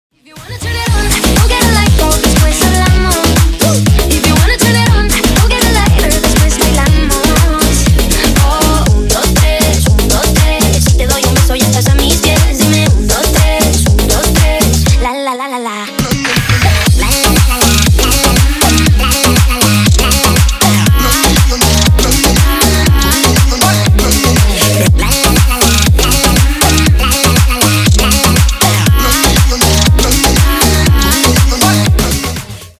Ремикс # Поп Музыка # Танцевальные